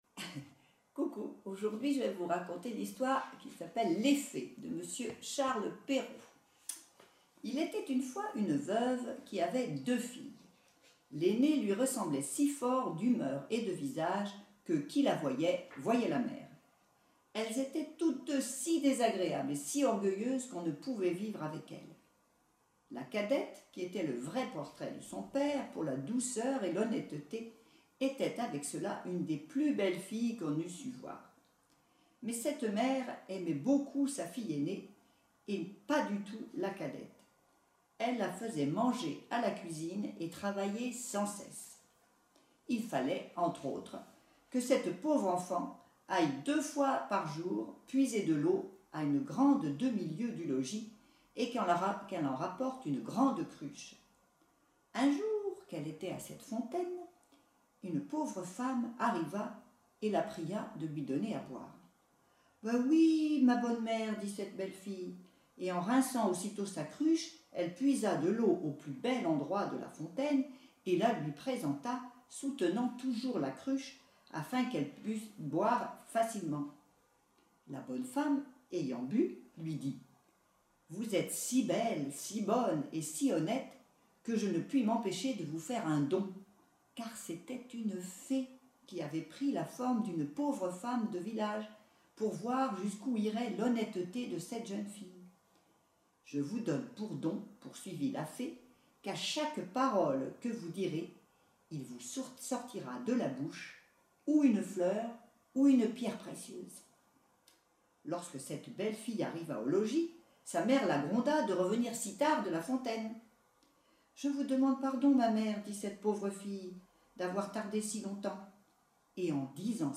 Une histoire racontée